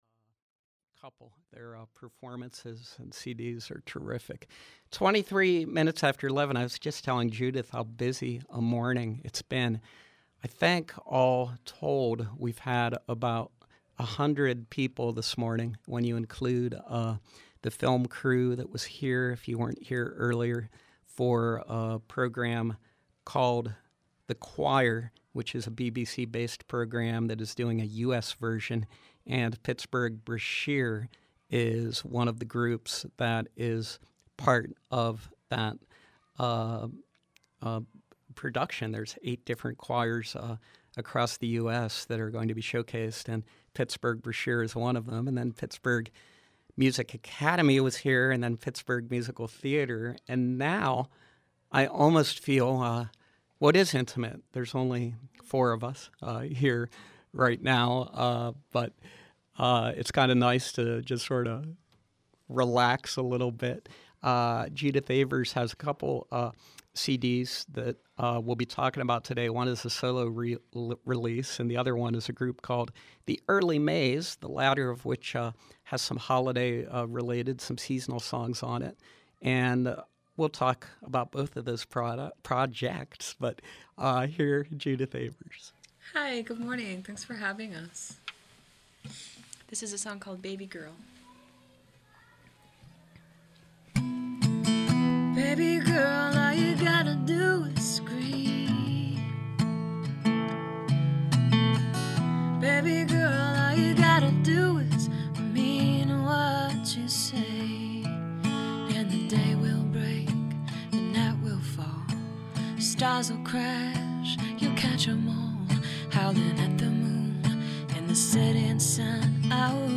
singer/songwriter